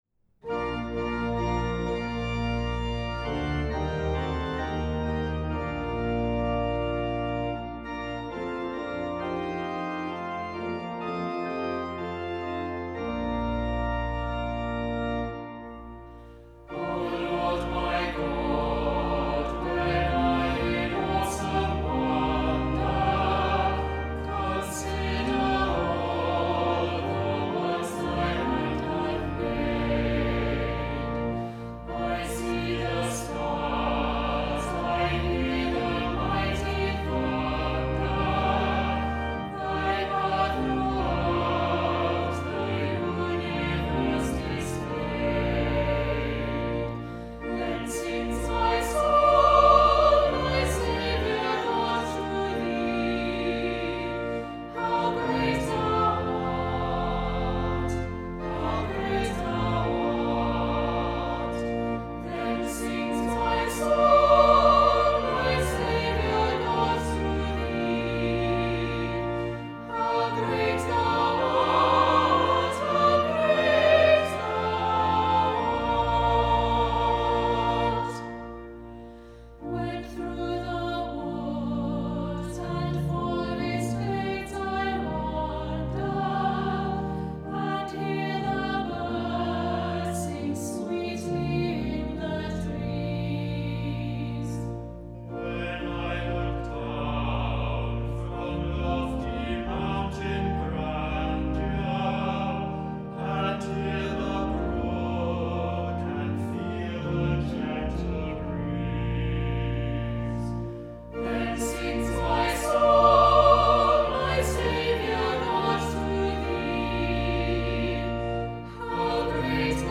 Hymns and songs were recorded remotely by the Choral Scholars of St Martin-in-the-fields in their homes, and edited together